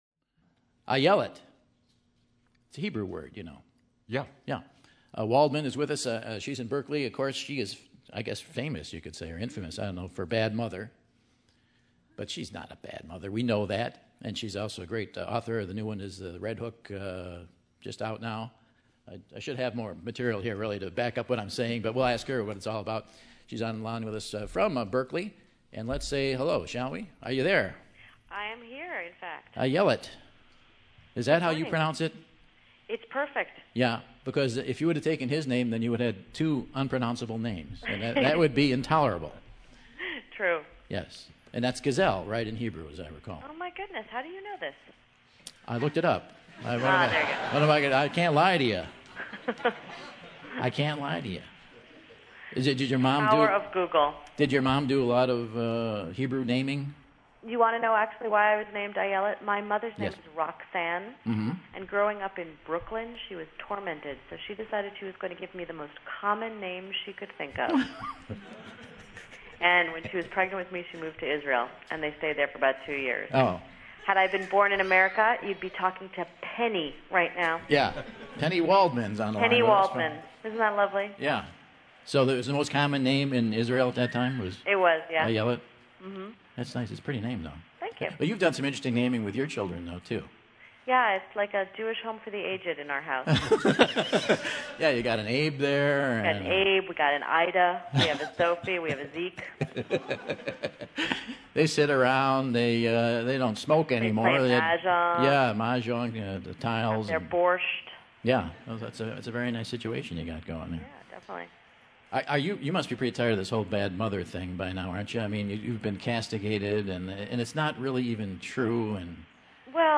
Bad Mother and Red Hook Road author, Ayelet Waldman phones in from Berkeley to chat with Michael about motherhood, her career arc to writer and being on Oprah!